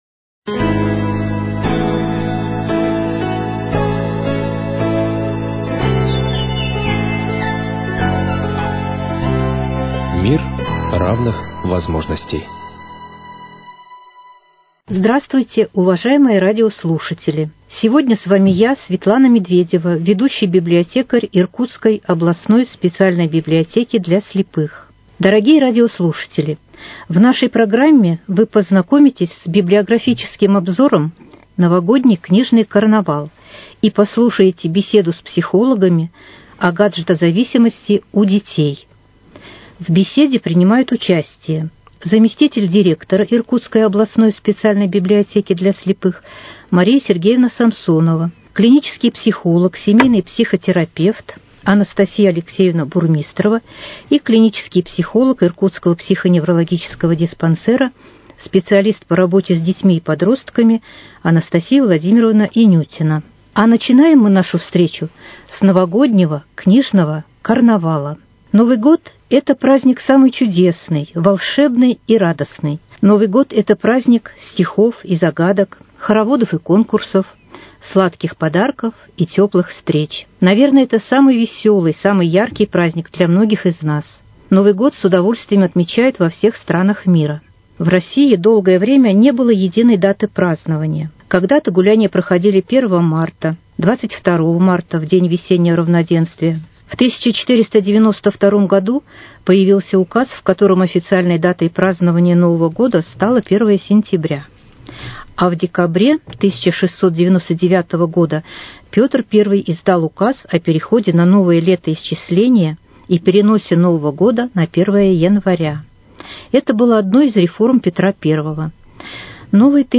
Совместный проект Иркутского радио и Иркутской областной специальной библиотеки для слепых.